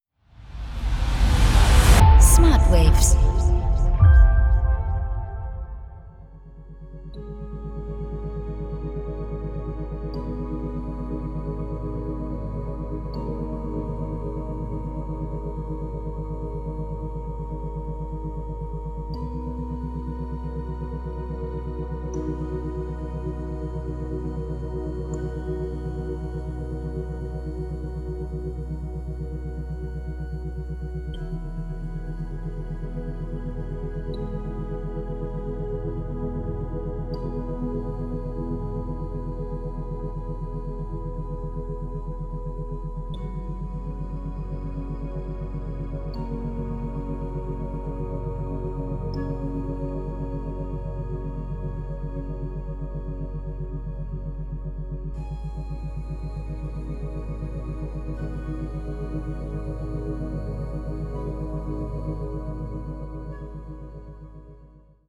tiefe und entspannende Hintergrundmusik
• Methode: Binaurale Beats
• Frequenz: 5,5-7 Hertz